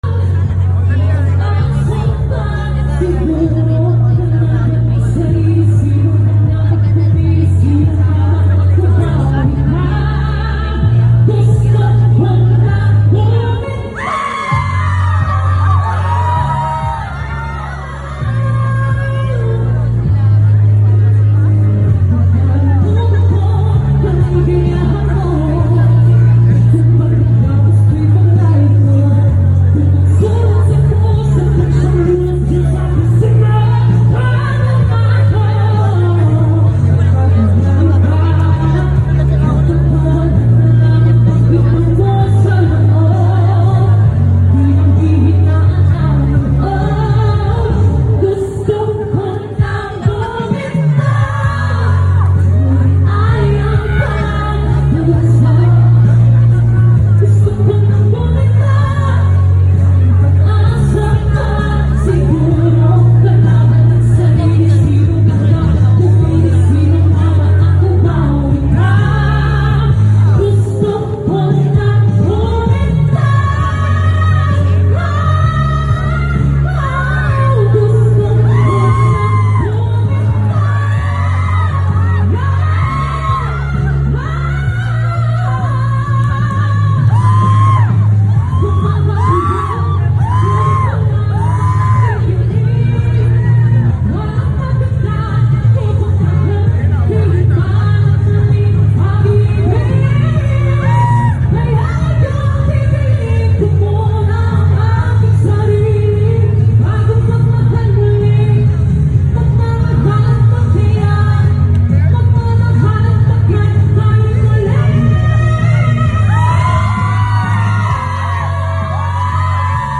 grabe ganda ng boses